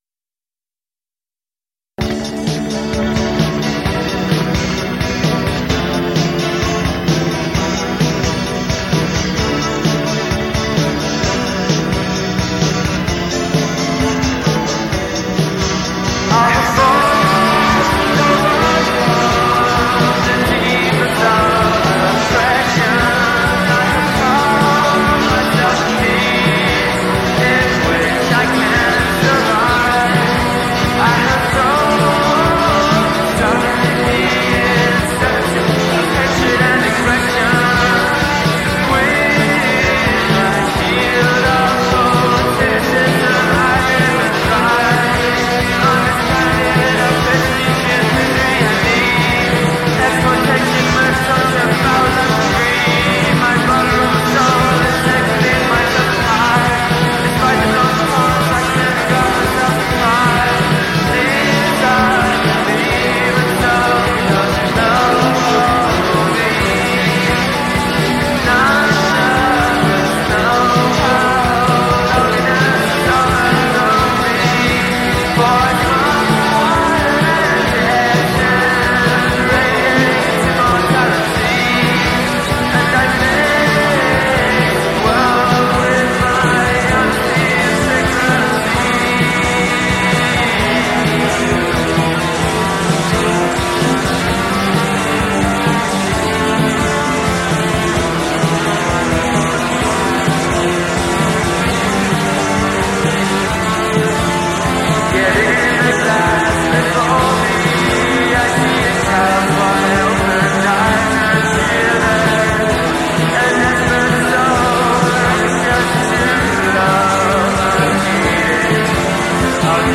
then, in a local music store i saw this imposing beauty on the left for £125. i got it home and started to record  some tracks with it. it was called the amstrad system 100, and it was a cheap midi hi-fi with 4 track recorder built in.
in use however it was disappointing. the recording quality was very poor, loads of noise and unbelievable wow and flutter - i never heard anything oscillate like this.
the track above was recorded with a borrowed bass, not very good quality.